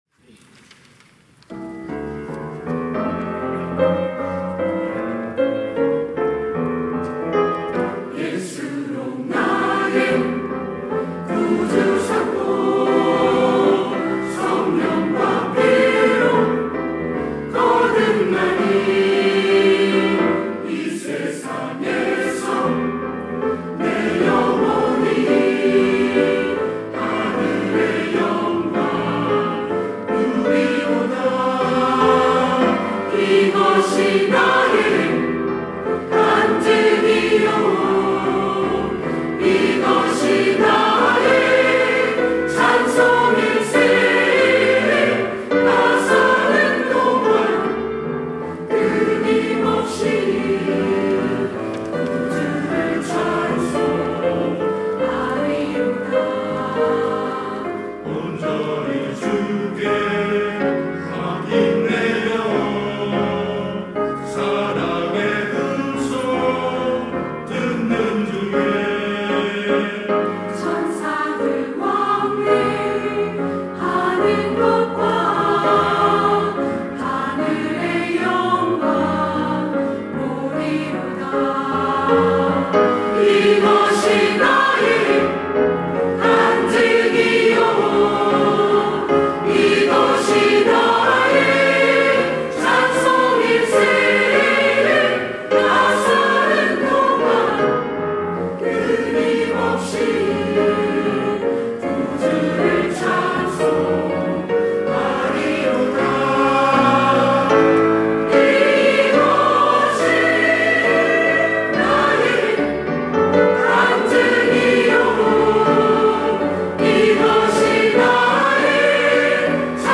시온(주일1부) - 예수로 나의 구주 삼고
찬양대